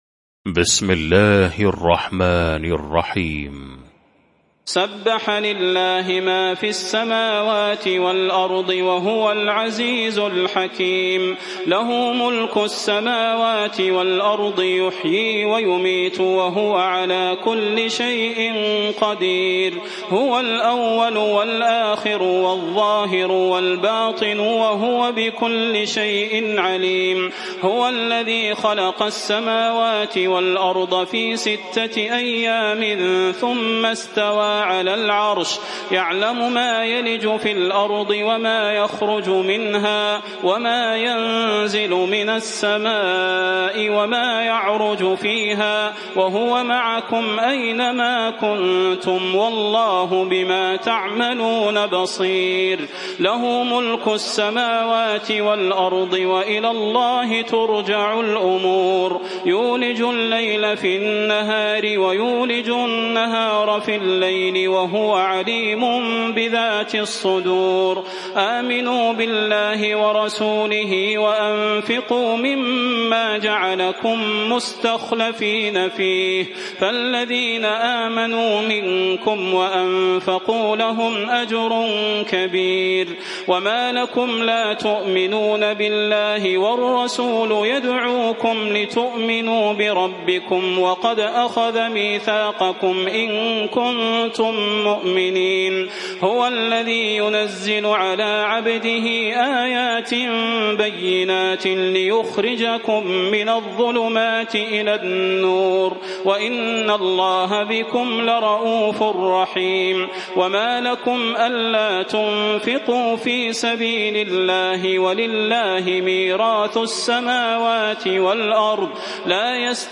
المكان: المسجد النبوي الشيخ: فضيلة الشيخ د. صلاح بن محمد البدير فضيلة الشيخ د. صلاح بن محمد البدير الحديد